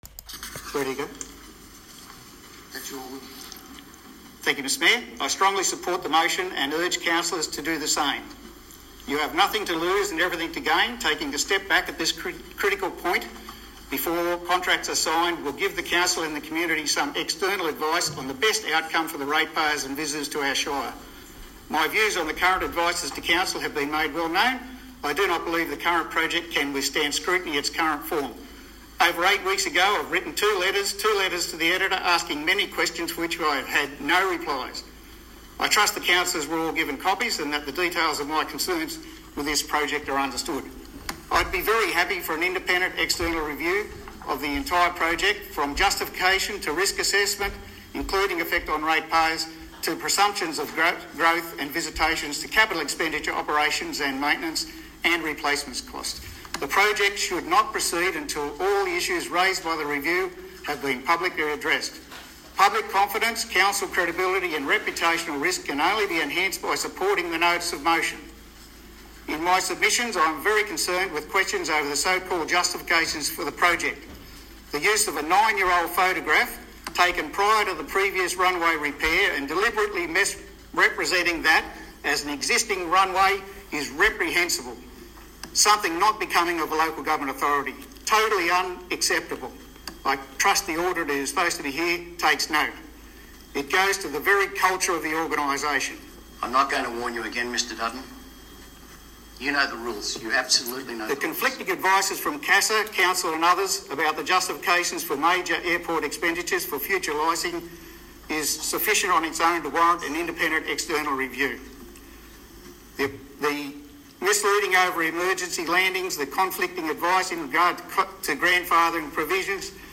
COUNCIL have released the audio file of the November meeting, which includes the motion relating to the airport.